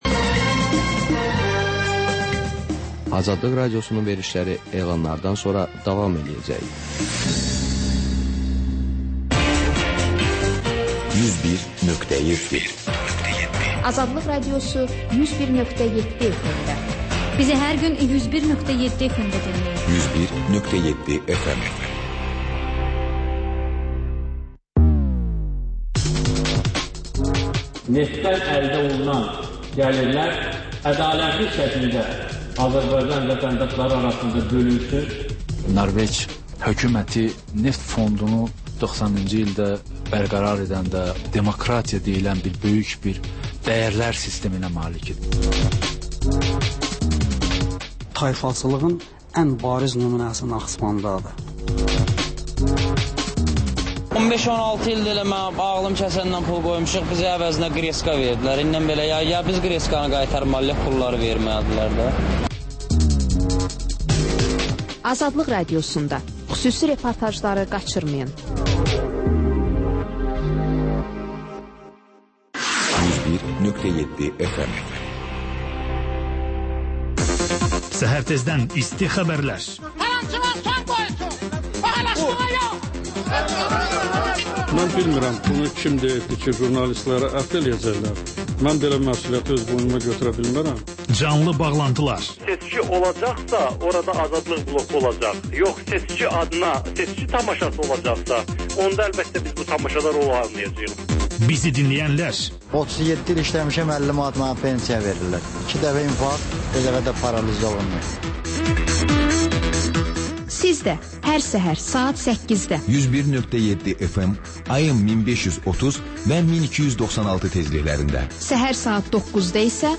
Xəbərlər, sonra CAN BAKI: Bakının ictimai və mədəni yaşamı, düşüncə və əyləncə həyatı…